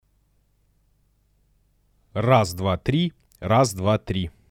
Шипит shure sm7b
Я новичок в этой теме, но потребовался хороший микрофон для записи голоса на ютуб, выбор пал на Sm7b, scarlett 2i2 и soyuz launcher По итогу имею какое-то очень громкое шипение на заднем фоне, посмотрел у других ребят на ютубе такой проблемы нет Как это можно исправить?
Семпл шума прилагаю Вложения семпл.mp3 семпл.mp3 149,6 KB · Просмотры: 2.707